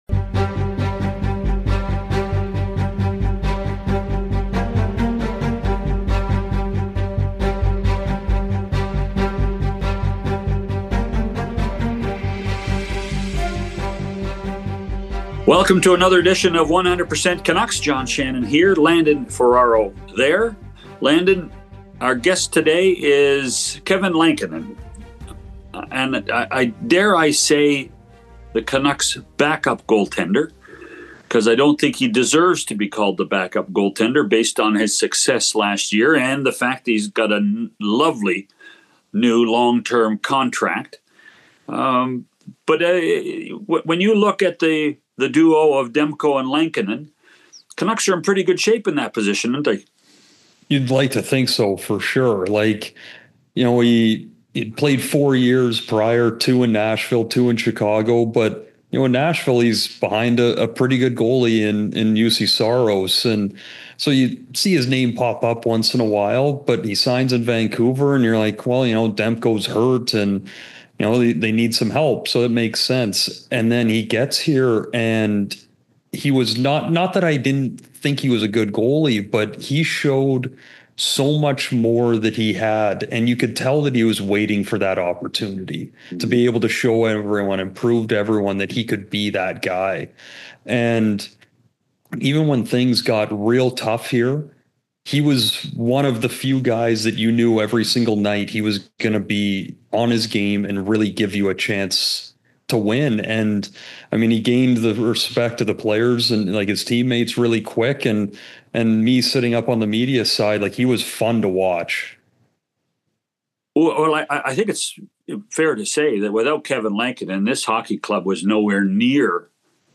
On this episode of 100% Canucks, John Shannon and Landon Ferraro welcome goaltender Kevin Lankinen for a conversation about his path to Vancouver and his outlook for the upcoming season.